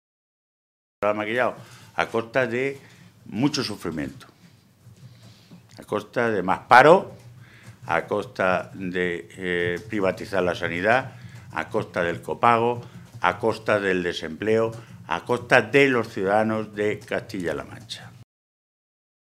Jesús Fernández Vaquero, Secretario de Organización del PSOE de Castilla-La Mancha
Cortes de audio de la rueda de prensa